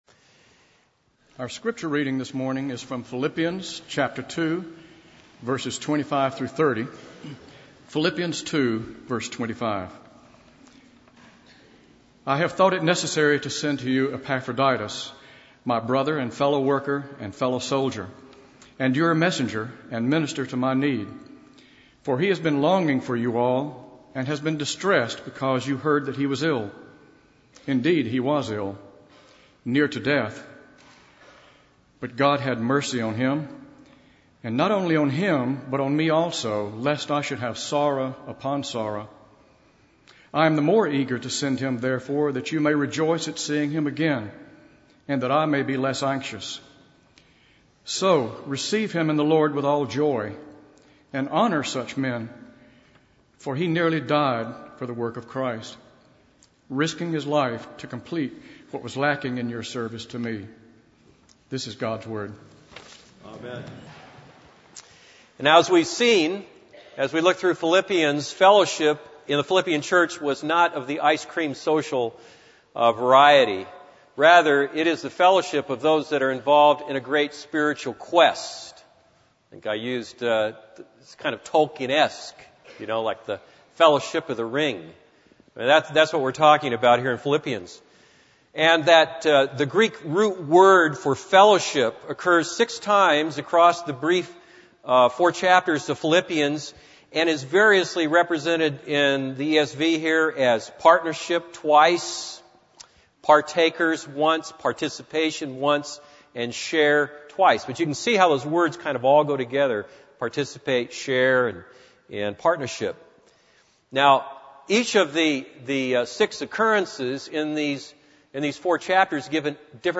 This is a sermon on Philippians 2:25-30.